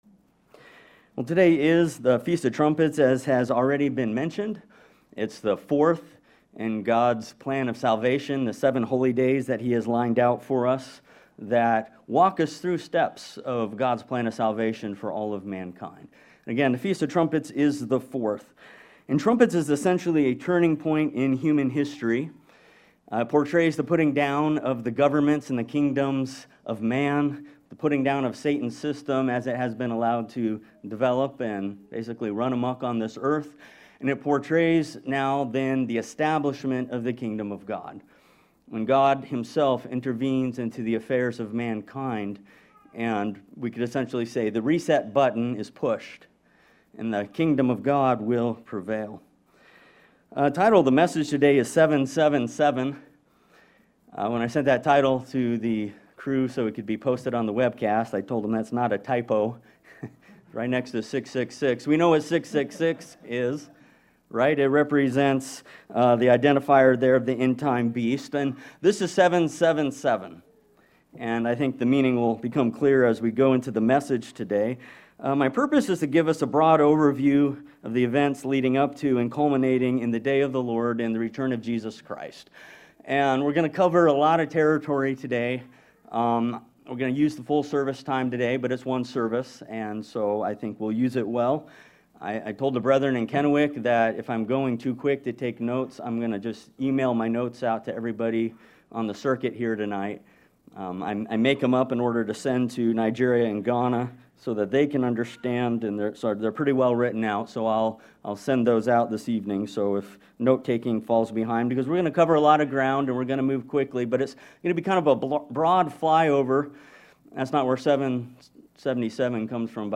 There are events culminating in the return of Jesus Christ and the establishment of the Kingdom of God that are prophesied to unfold in patterns of seven. This sermon explores three important sets of seven found in the book of Revelation.